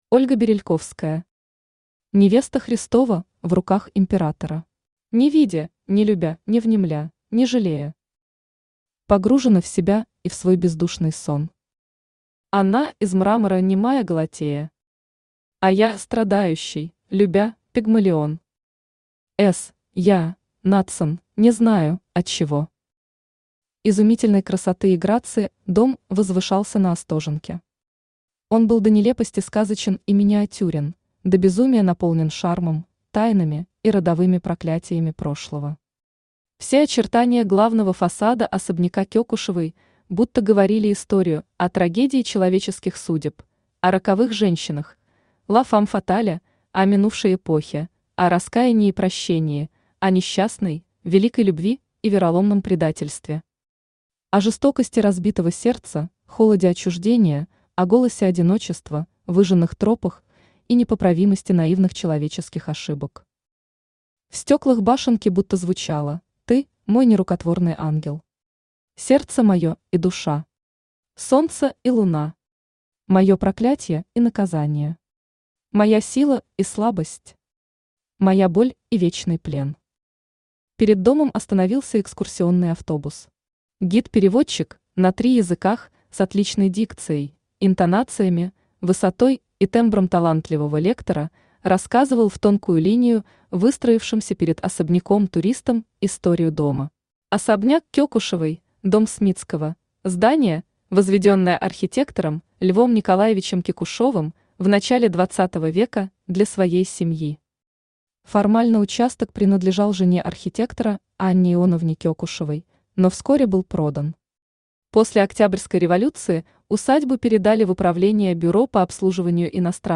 Аудиокнига Невеста Христова в руках Императора | Библиотека аудиокниг
Aудиокнига Невеста Христова в руках Императора Автор Ольга Ильинична Берельковская Читает аудиокнигу Авточтец ЛитРес.